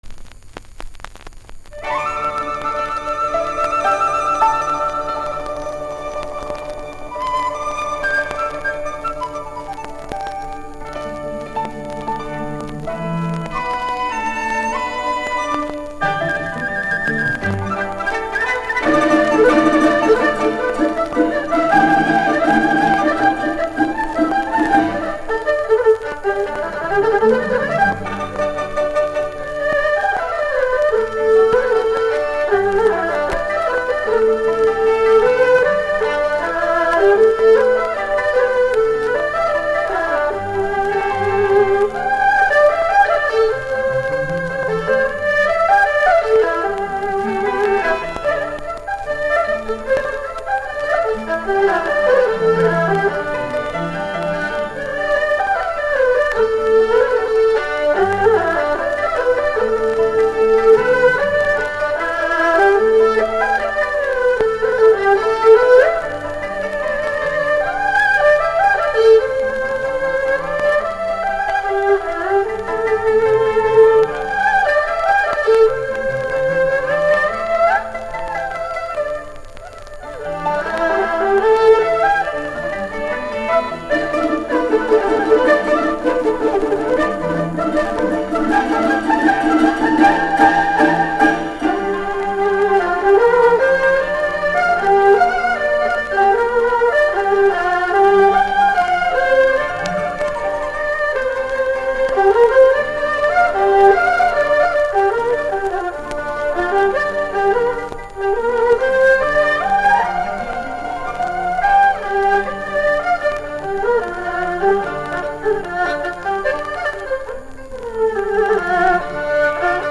格式：33转黑胶转128k/mp3